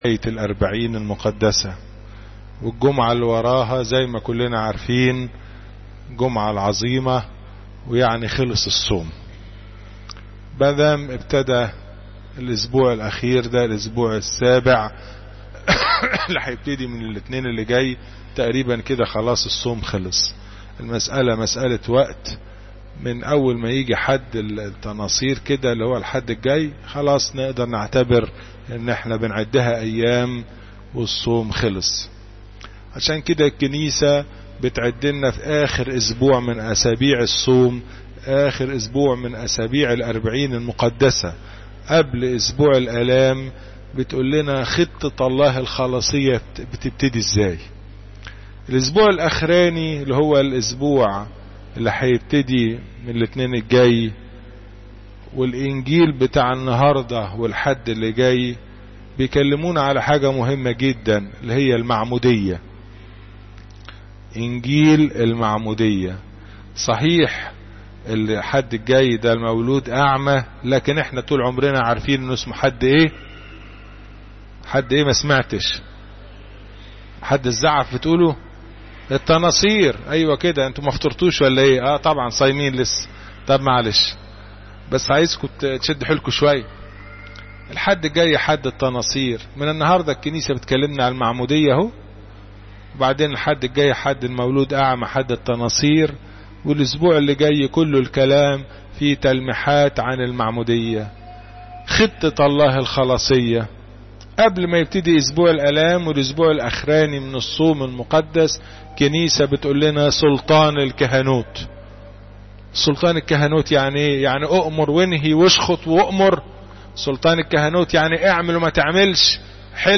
Church's Holly Masses